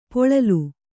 ♪ poḷalu